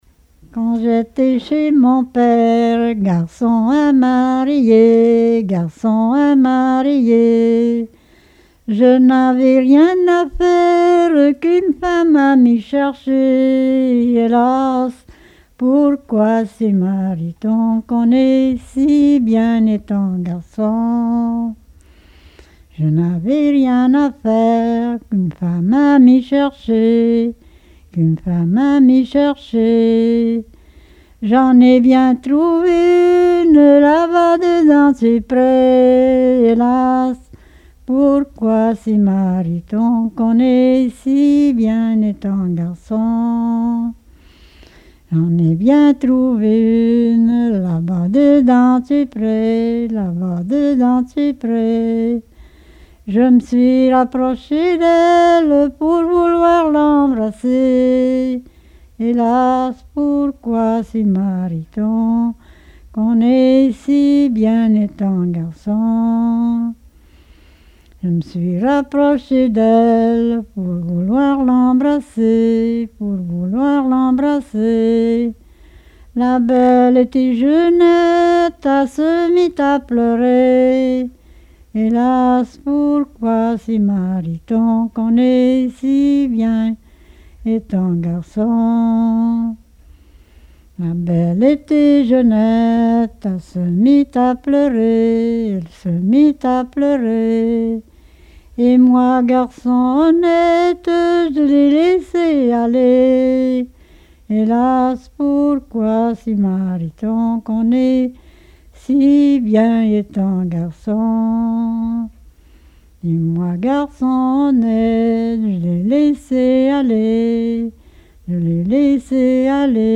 circonstance : fiançaille, noce
Pièce musicale inédite